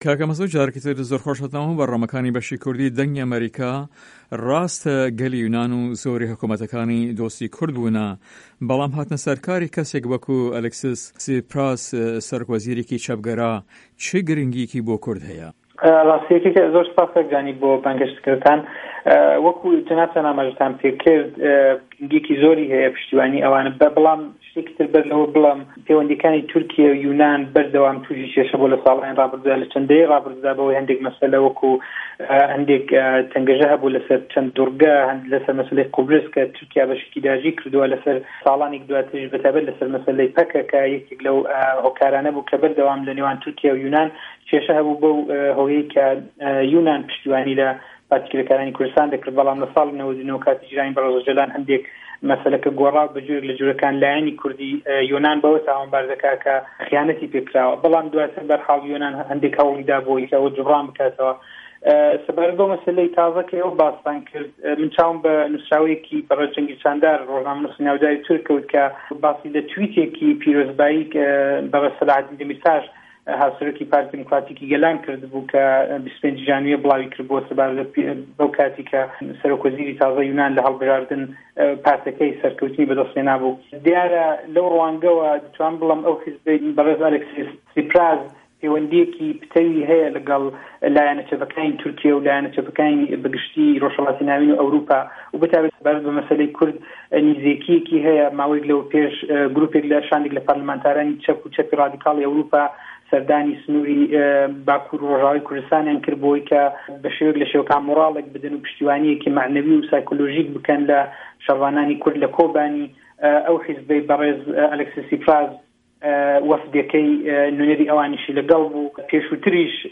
هه‌رێمه‌ کوردیـیه‌کان - گفتوگۆکان
هه‌ڤپه‌ێڤینێکدا